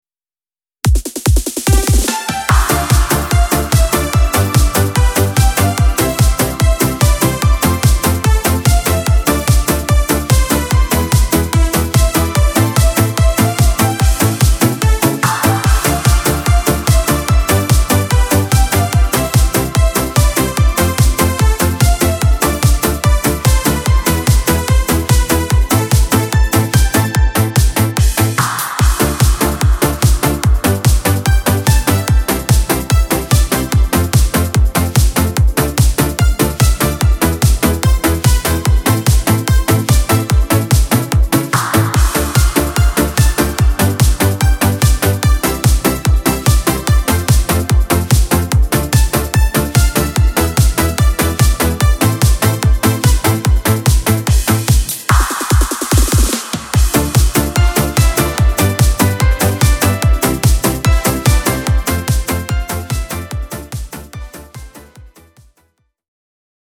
stylizowaną na najlepszy okres disco
Disco Polo